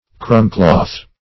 Search Result for " crumbcloth" : The Collaborative International Dictionary of English v.0.48: Crumbcloth \Crumb"cloth`\ (-kl[o^]th`; 115), n. A cloth to be laid under a dining table to receive falling fragments, and keep the carpet or floor clean.
crumbcloth.mp3